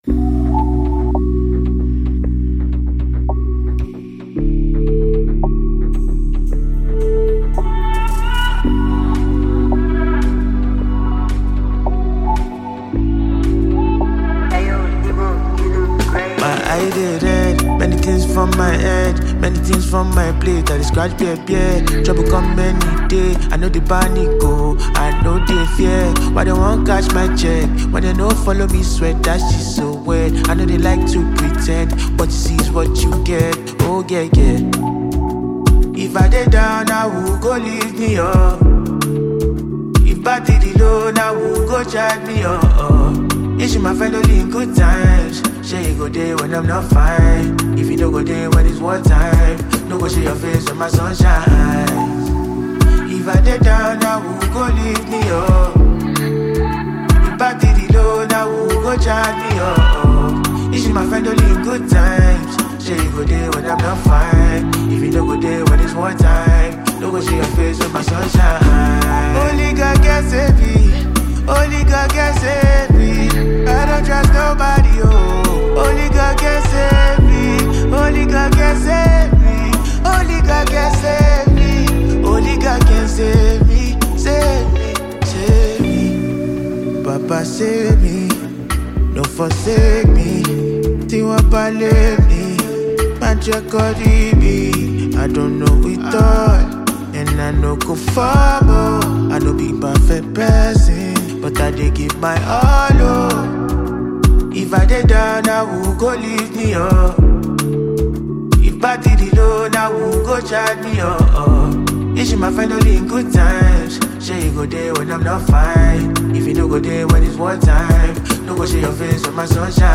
With his captivating vocals and deeply emotive lyrics
Afrobeat and R&B
powerful and introspective song
soulful and emotive performance